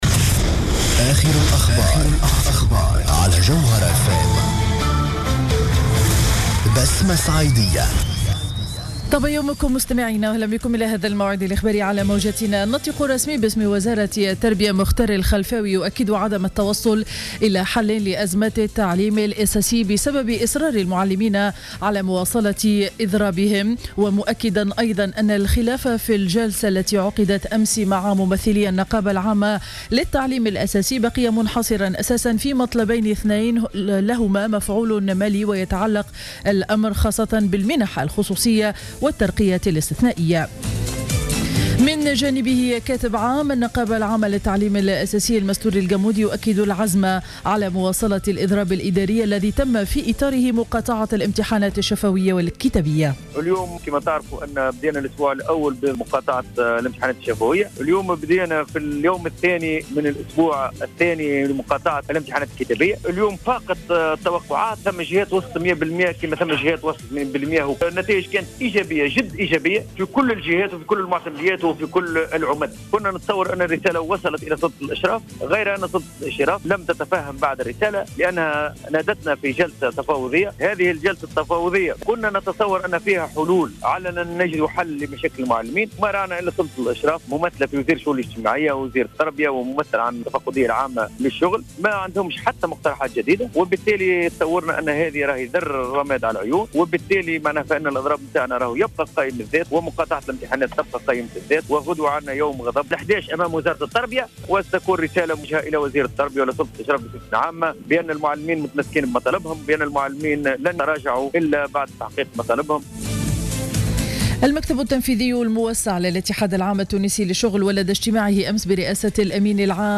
نشرة أخبار السابعة صباحا ليوم الثلاثاء 09 جوان 2015